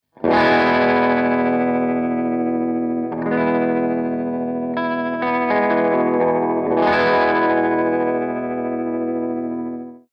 013_AC30_VIBRATO1_P90